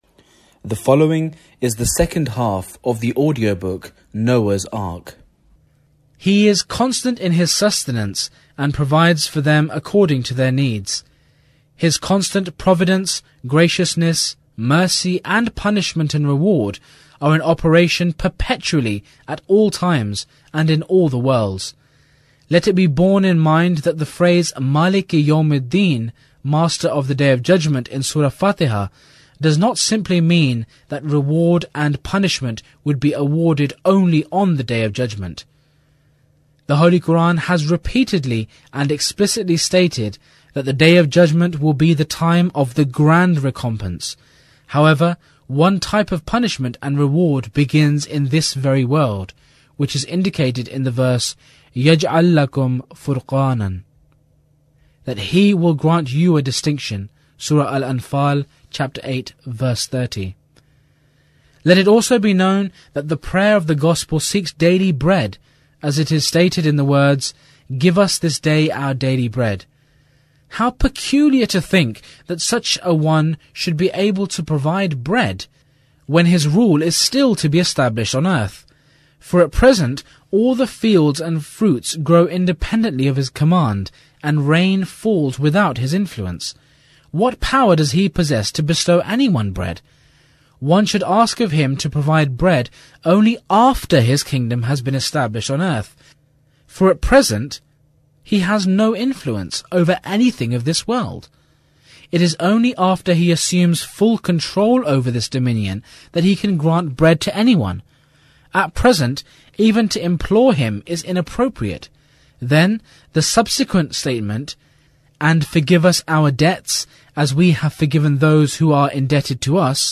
Audiobook: Noah’s Ark – An Invitation to Faith